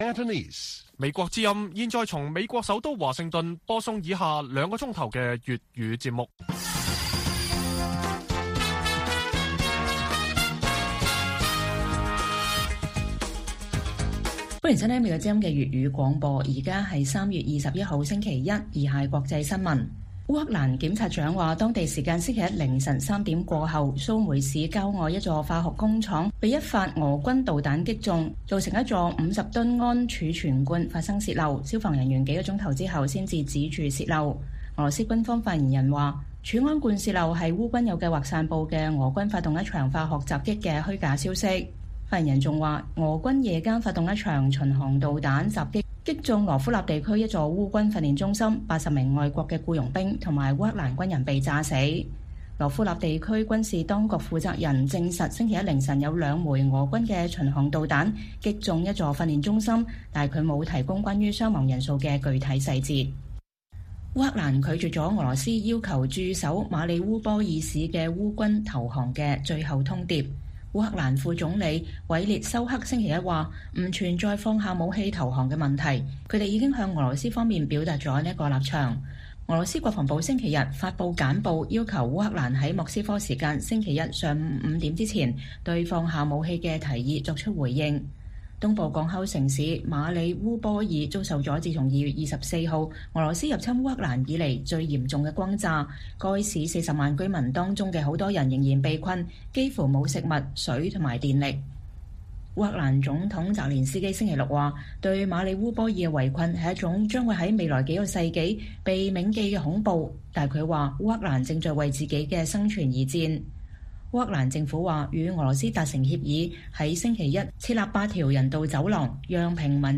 粵語新聞 晚上9-10點: 香港特首宣佈暫緩全民強檢